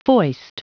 Prononciation du mot foist en anglais (fichier audio)
Prononciation du mot : foist